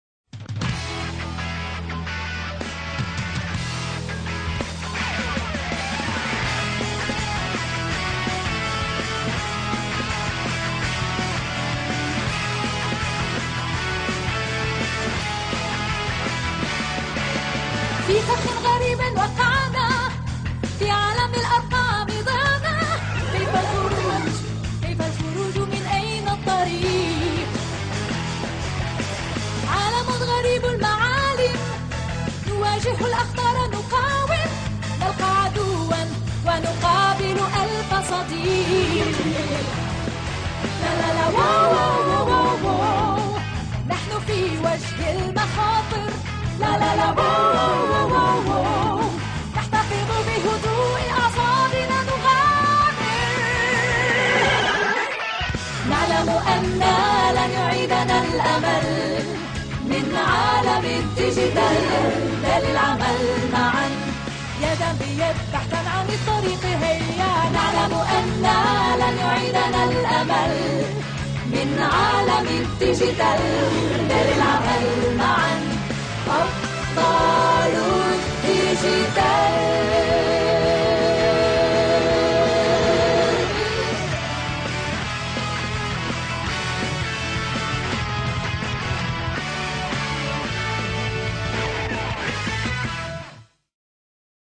أبطال الديجيتال الجزء 4 - الحلقة 1 مدبلجة